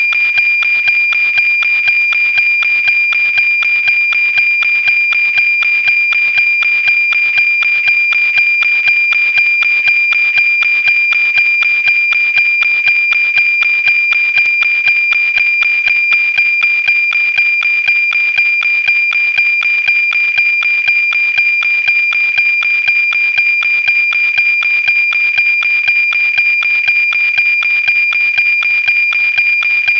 APT - Automatic Picture Transmission
AM-Signal (WAV, 16kHz, 30s, 938kB)